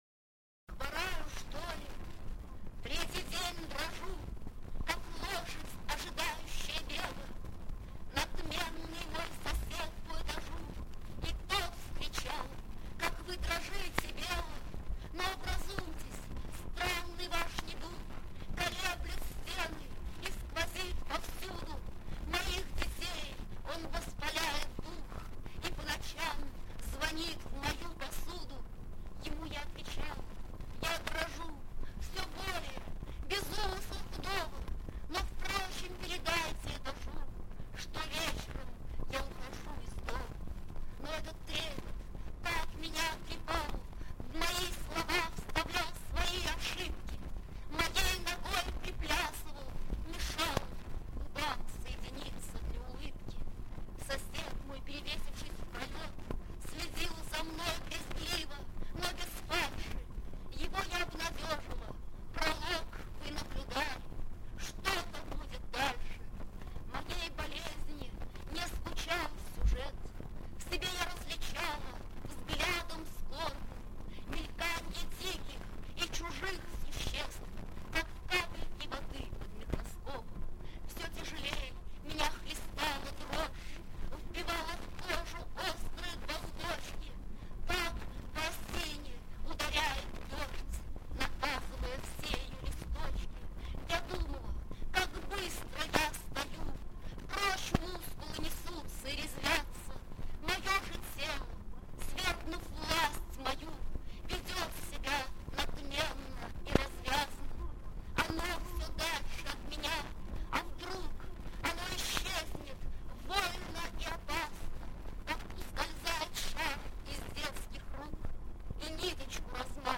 bella-ahmadulina-oznob-chitaet-avtor